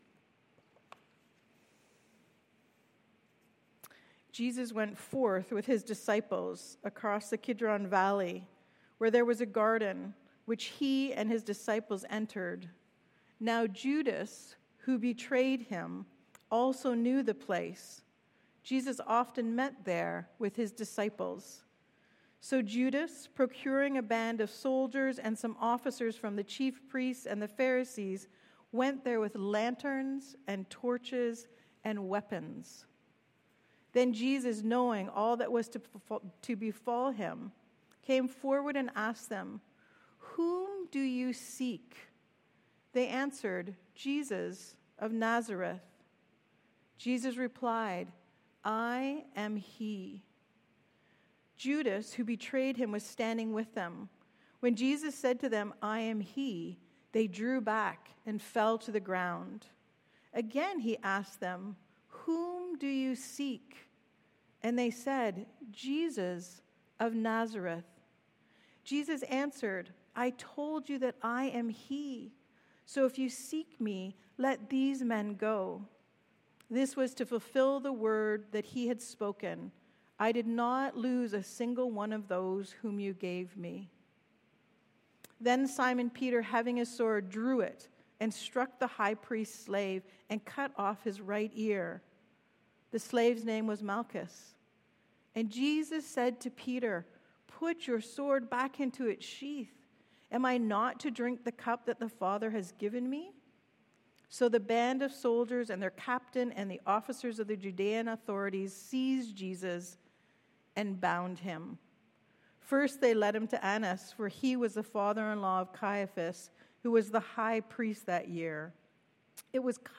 Sermons | The Gathering Church
Good Friday, April 18th, 2025
We join together for a time of remembrance of Christ's death. We will begin our service at 10:30, entering into a time of quiet reflection through music, scripture, words and communion.